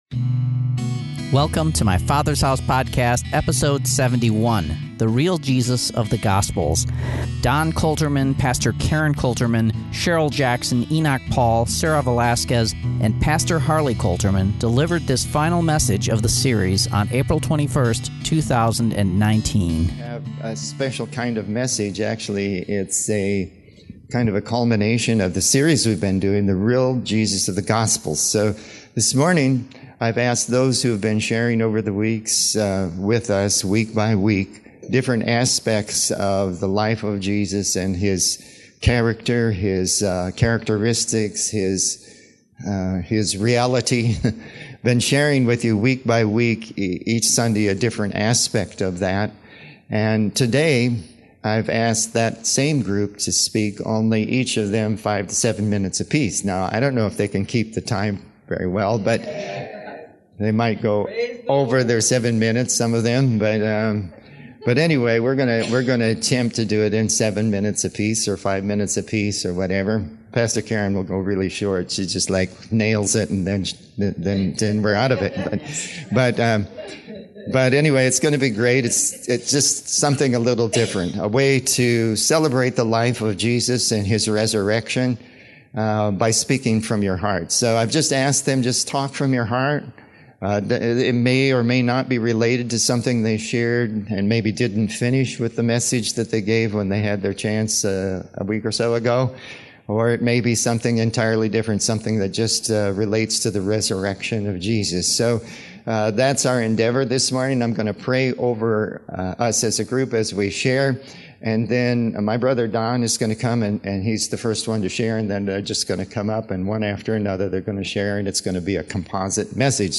Today’s message is a composite of the Real Jesus of the Gospels series.